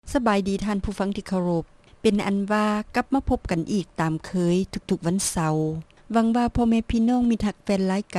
ຣາຍການໜໍລຳປະຈຳສັປະດາ